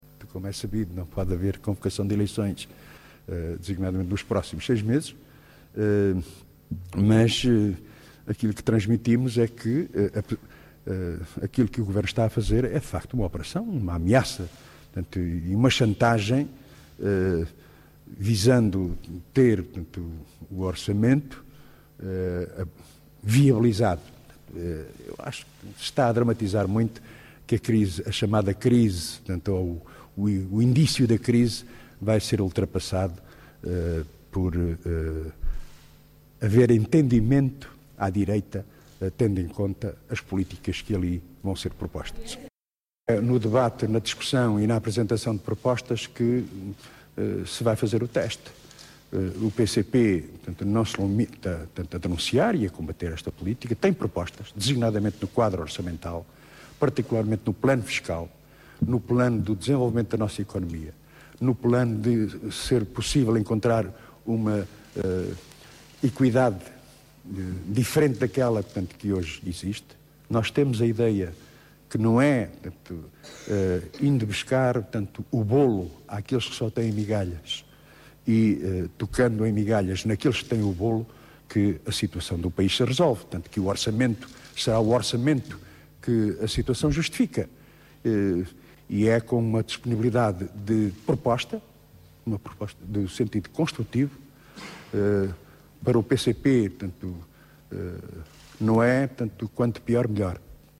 Declarações de Jerónimo de Sousa sobre a audiência com o Presidente da República